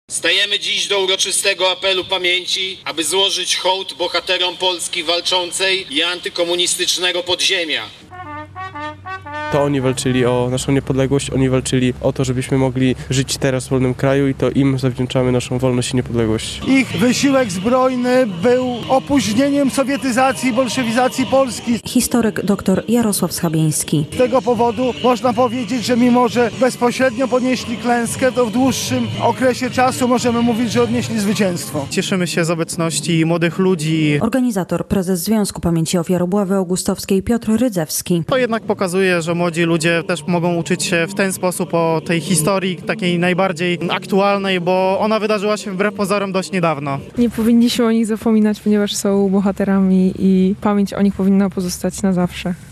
Parlamentarzyści, samorządowcy, żołnierze i mieszkańcy Suwałk oddali hołd Żołnierzom Wyklętym